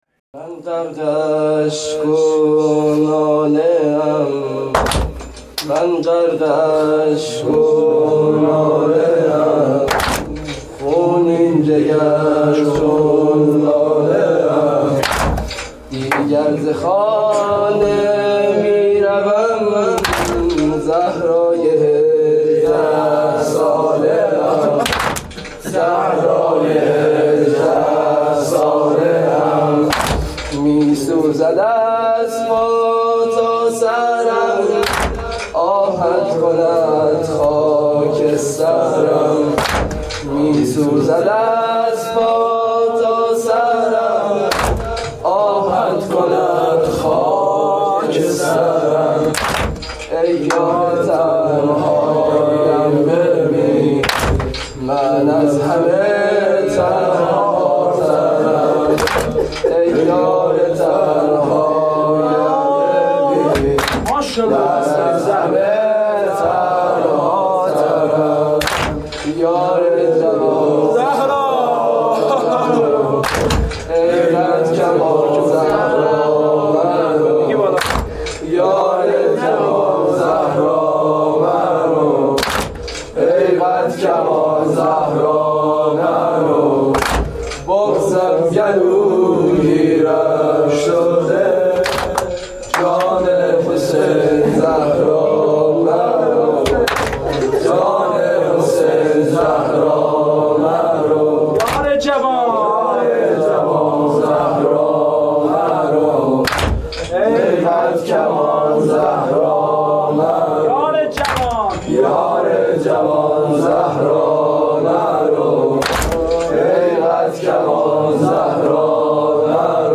شب دوم فاطمیه 1393, مداحی فاطمیه